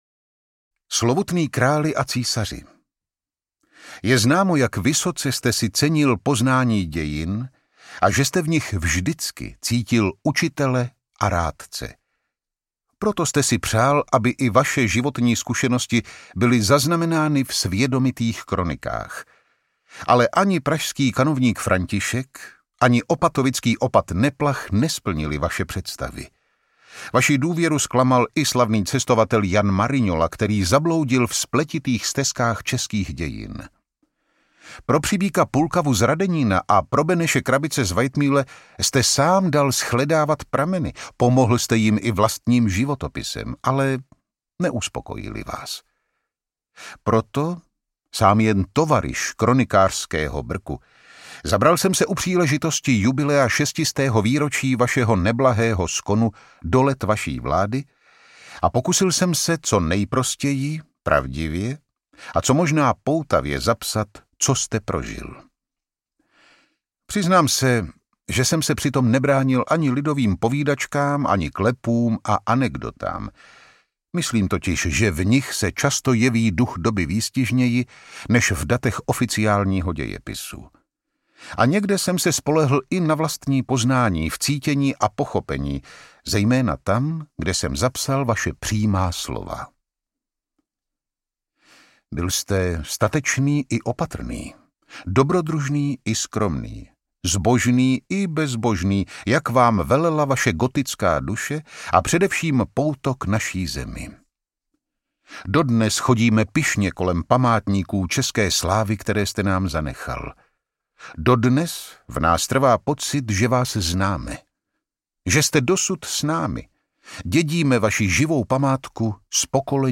Kronika života a vlády Karla IV., krále českého a císaře římského audiokniha
Ukázka z knihy
| Vyrobilo studio Soundguru.